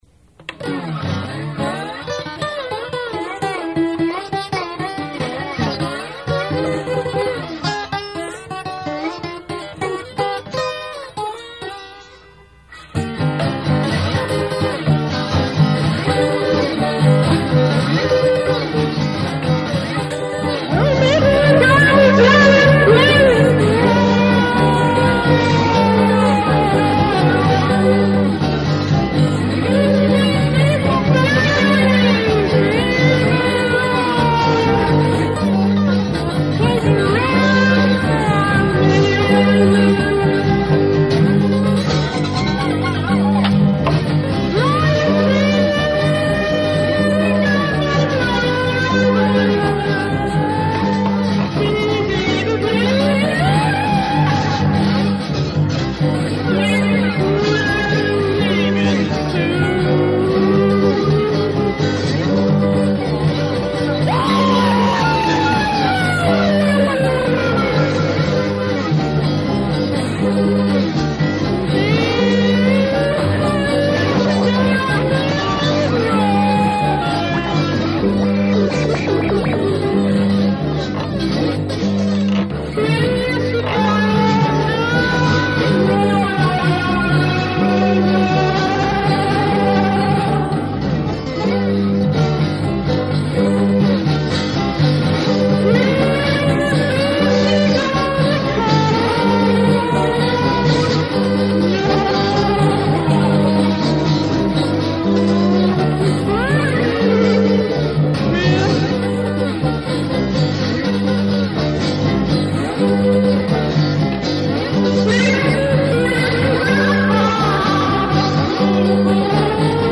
about a fun nonsense song we did back in 1985 called Hats Off To Charles Obscure
while most of them were (and still are) unlistenable (as I hope you are realizing while you play HOTCO)